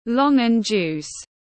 Nước ép nhãn tiếng anh gọi là longan juice, phiên âm tiếng anh đọc là /lɔɳgən ˌdʒuːs/
Longan juice /lɔɳgən ˌdʒuːs/